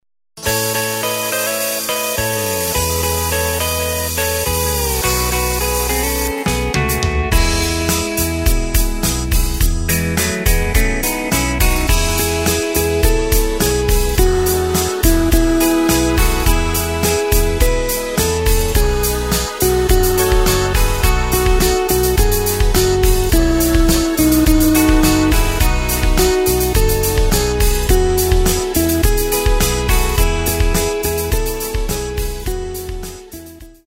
Takt:          4/4
Tempo:         105.00
Tonart:            E
Schlager aus dem Jahr 1973!